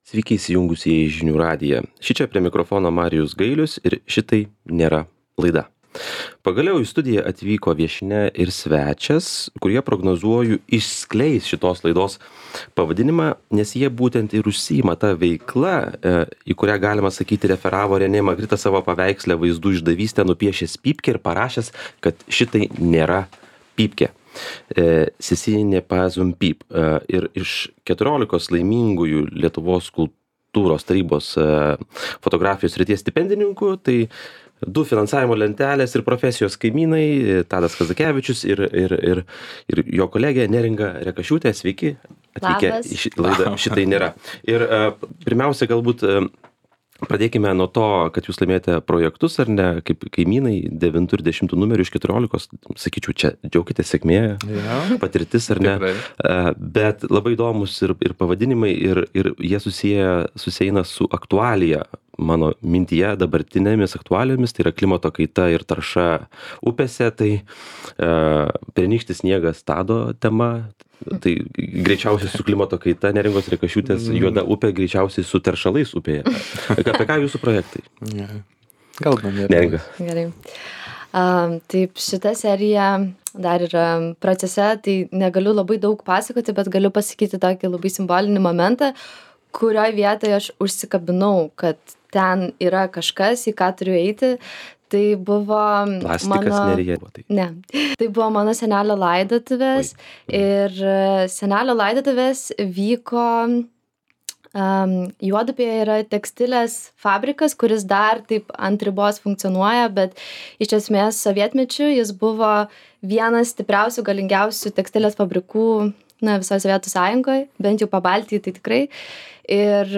Pokalbis su jaunais ir pripažintais